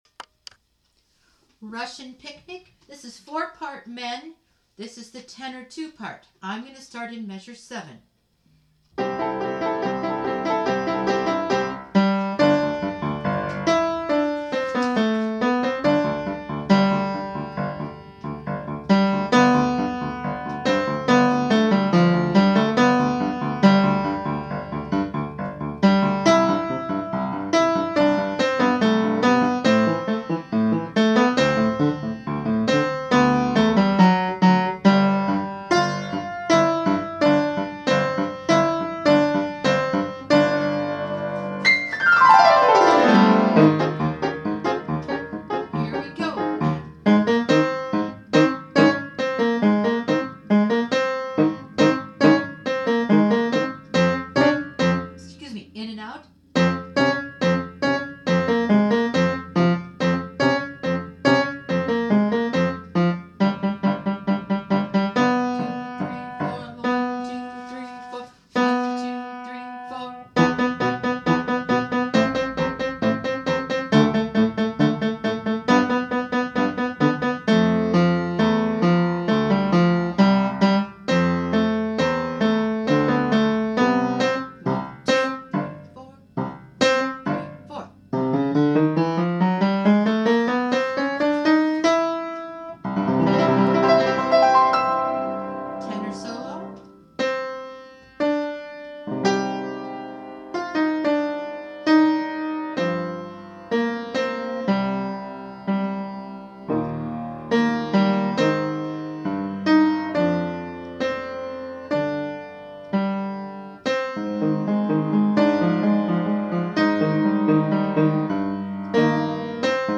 All-Unit Girls & Guys songs
Tenor 2
03-Russian-Picnic-TTBB-T2.mp3